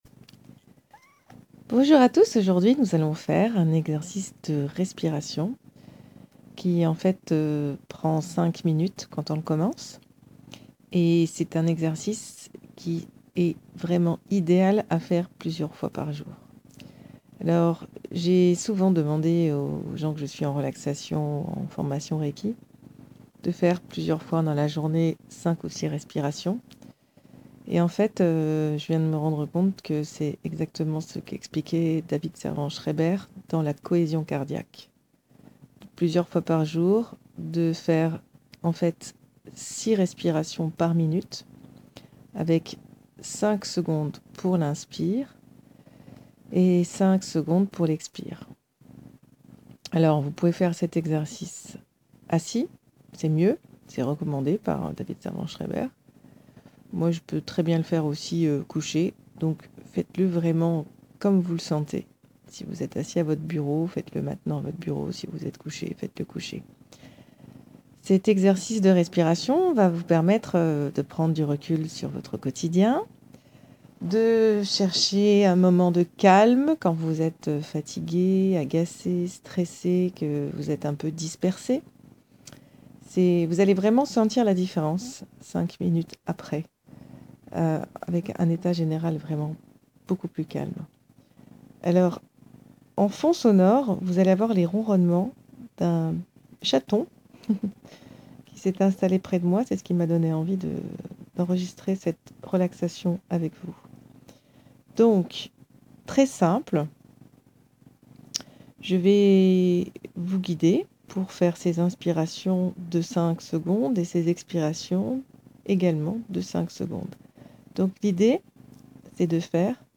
Je vous guide à la détente….